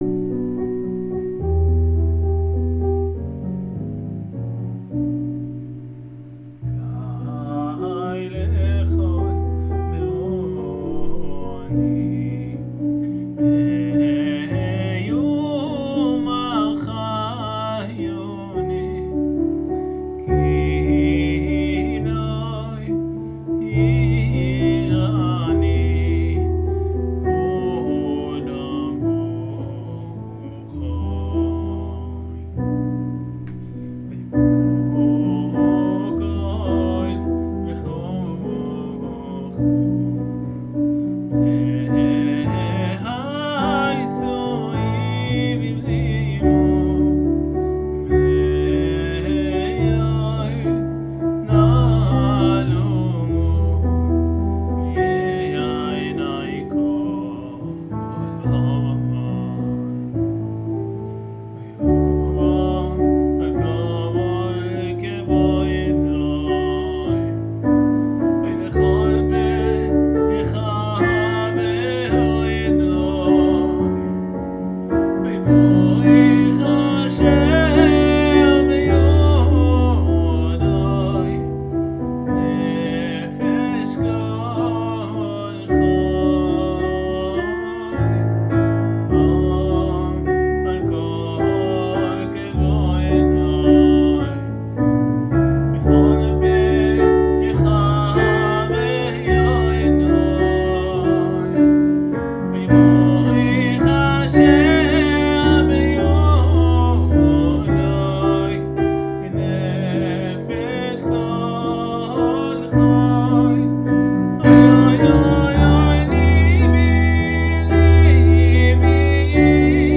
הקלטת סקיצה עם פסנתר אשמח לשמוע חוות דעתכם!
מדהים מדהים __ לא שומעים כל כך טוב את השירה אבל הפסנתר מאוד מאוד יפה